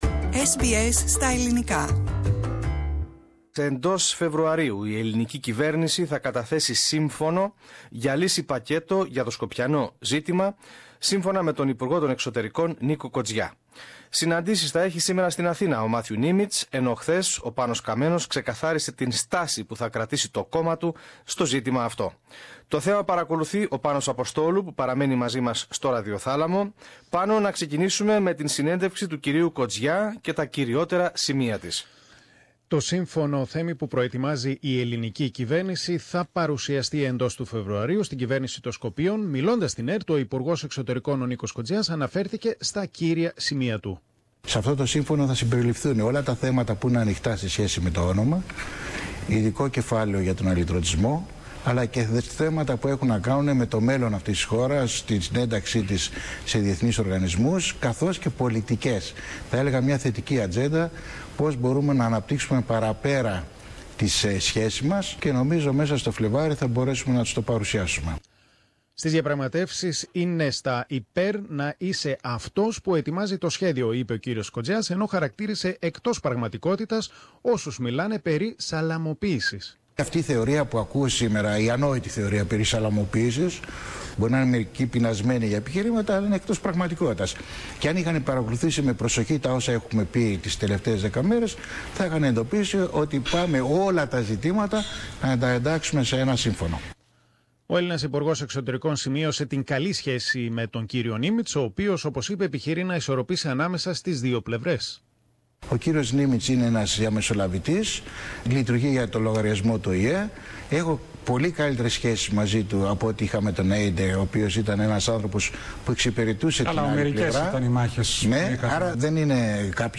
Συνέντευξη Κοτζιά Εντός Φεβρουαρίου η ελληνική κυβέρνηση θα καταθέσει σύμφωνο για λύση-πακέτο για το Σκοπιανό ζήτημα, σύμφωνα με τον υπουργό Εξωτερικών Νίκο Κοτζιά.